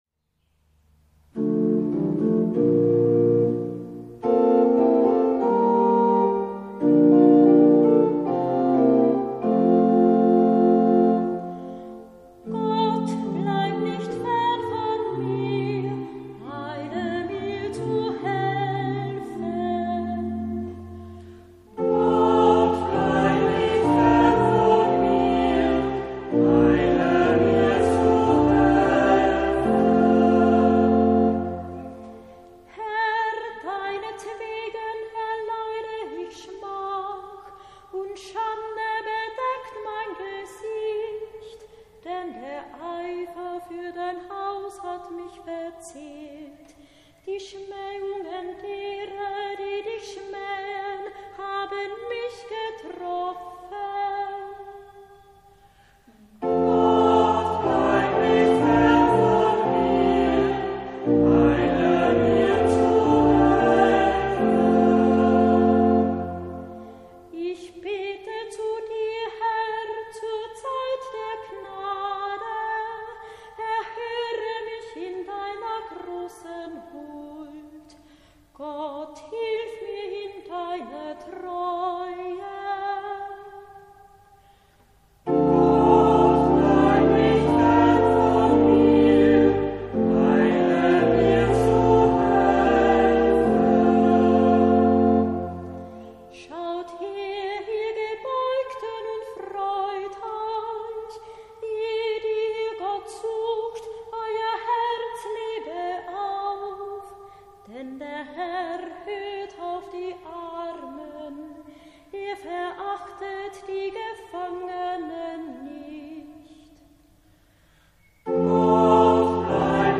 Antwortpsalmen Juni 2014